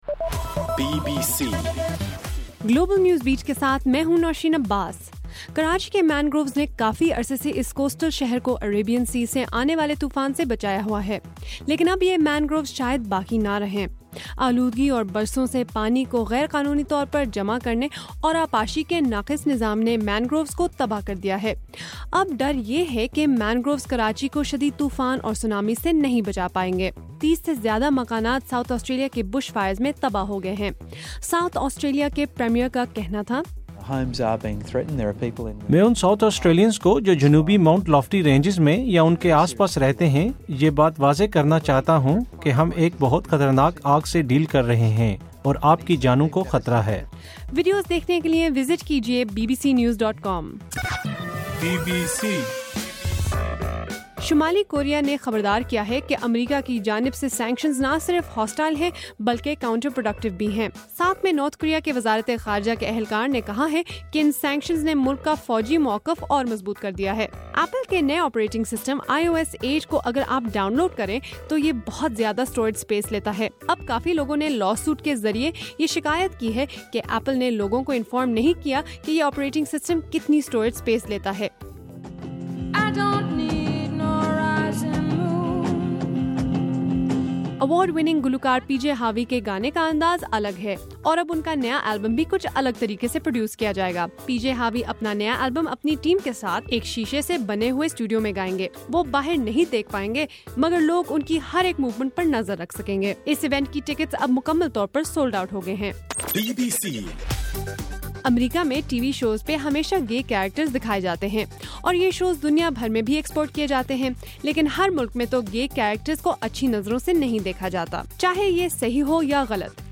جنوری 4: رات 8 بجے کا گلوبل نیوز بیٹ بُلیٹن